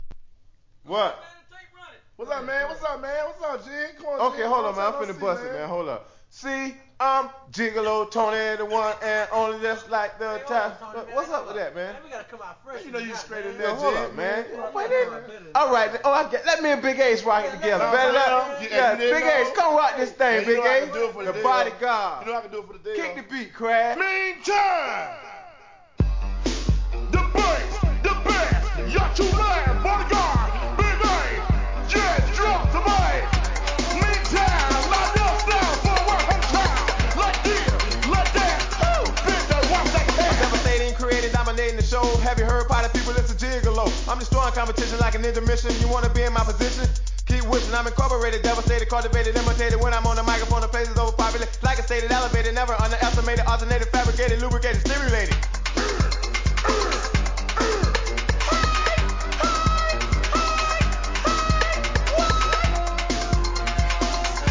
HIP HOP/R&B
マイアミ・ベース